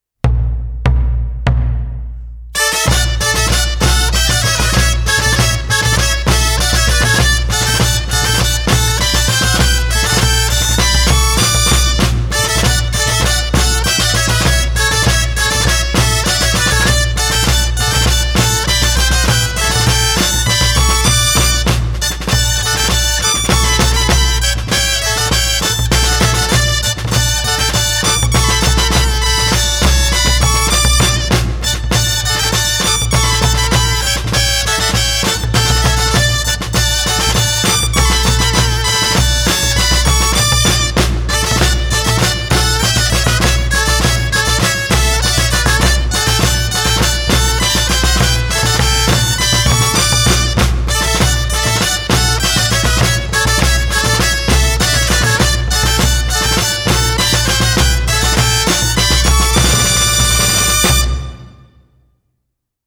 16.1 BALL DE TURCS I CAVALLETS DE TARRAGONA CERCAVILA Dolçainers Tresmall
Santa Tecla Tarragona